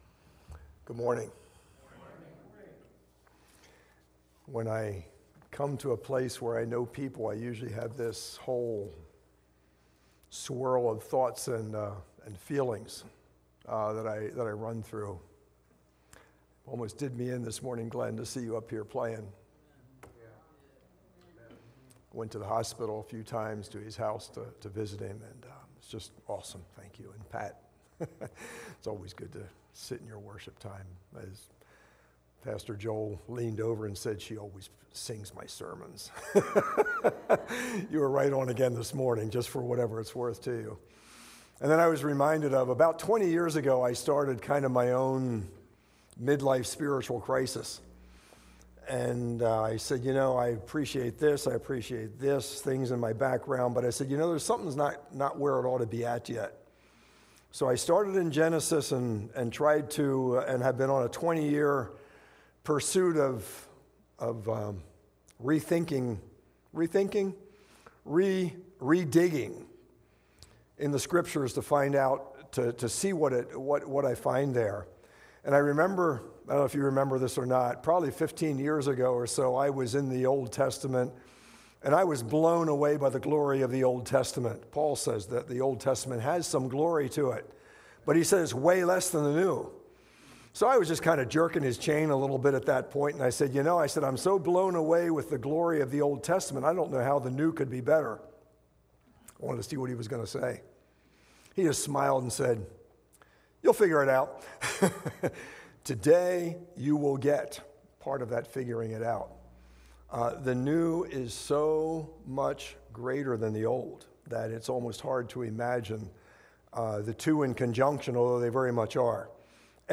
Sermons | Spring City Fellowship